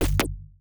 UIClick_Menu Double Hit Rumble Tail 04.wav